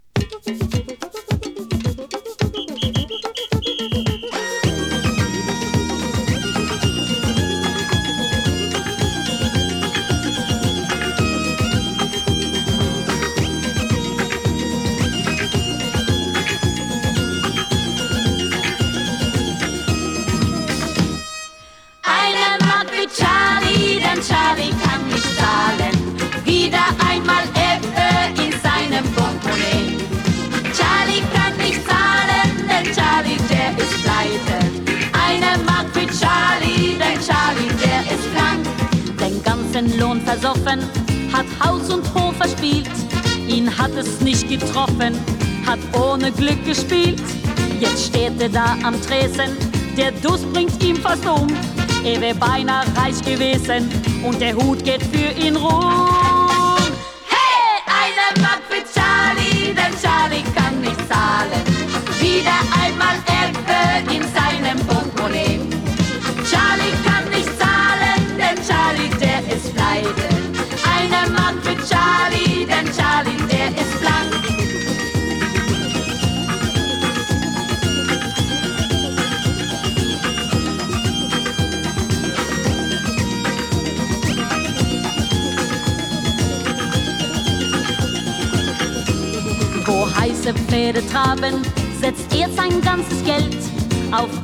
ノルウェー出身のボーカリストによるドイツ語録音。